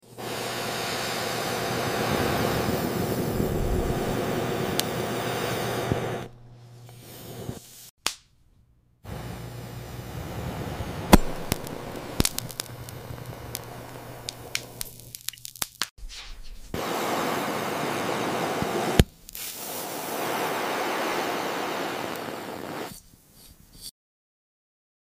Cutting Gems & Gold sound effects free download
Cutting Gems & Gold - Visual ASMR